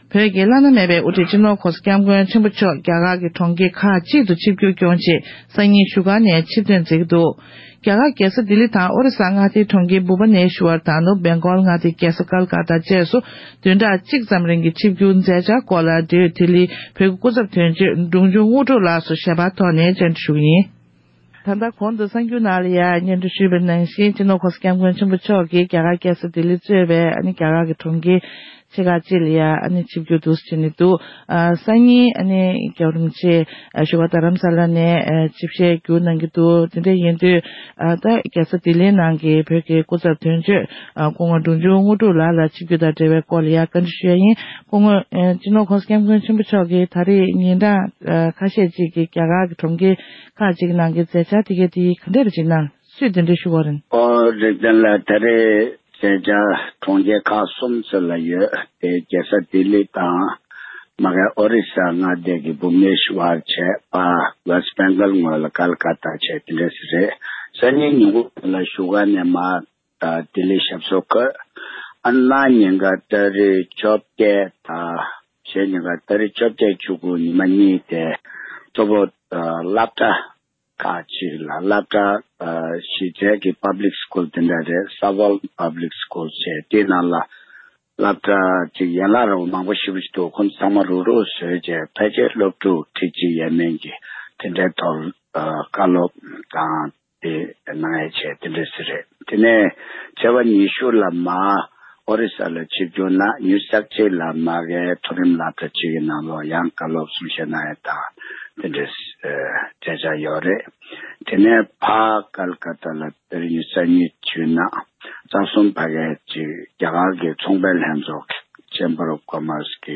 སྒྲ་ལྡན་གསར་འགྱུར།
ཞིབ་ཕྲ་ལྡི་ལི་དོན་གཅོད་འབྲོང་ཆུང་དངོས་གྲུབ་ལགས་སུ་བཅར་འདྲི་ཞུས་བར་གསན་རོགས།